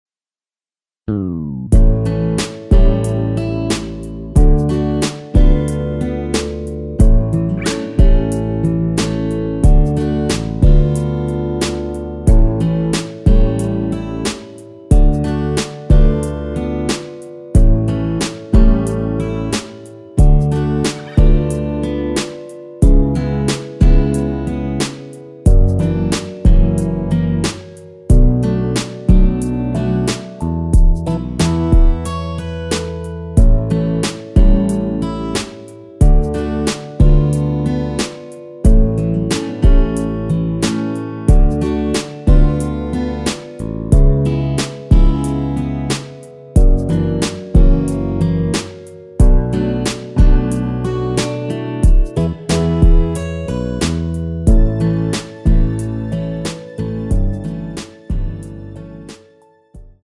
여자키에서 -1 더내린 MR 입니다.
앞부분30초, 뒷부분30초씩 편집해서 올려 드리고 있습니다.
중간에 음이 끈어지고 다시 나오는 이유는